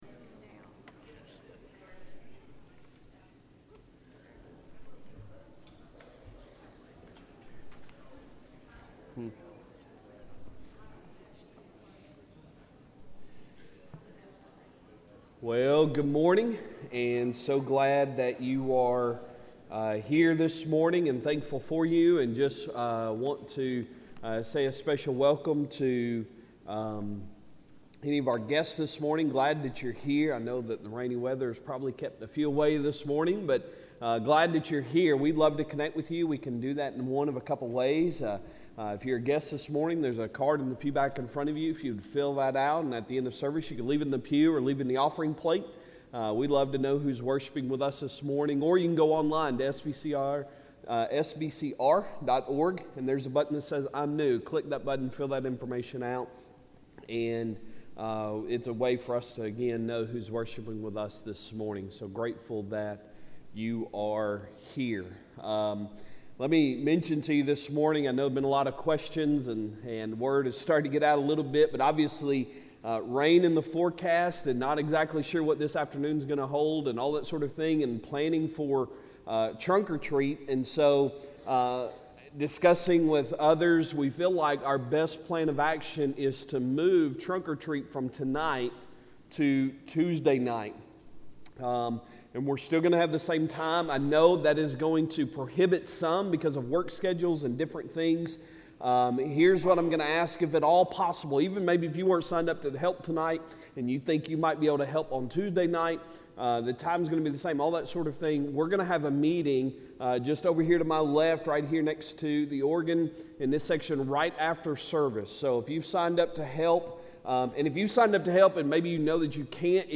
Sunday Sermon October 29, 2023